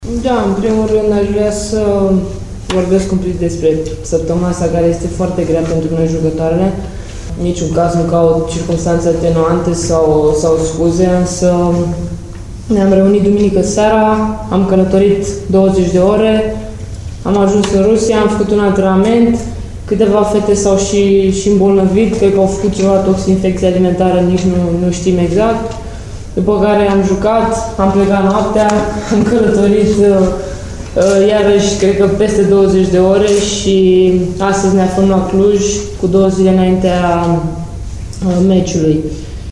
Căpitanul echipei naţionale de handbal senioare a României, Cristina Neagu, a declarat, că îşi doreşte foarte mult o revanşă în returul cu Rusia, o victorie în special pentru fani. În conferinţa de presă dinaintea jocului de la Cluj, din preliminariile europene, Neagu a vorbit despre o săptămână obositoare în jurul acestei duble întâlniri cu Rusia; prima partidă a fost pierdută miercuri, la Togliati, cu 30-25, iar revanșa e duminică, de la ora 18,30 la Cluj: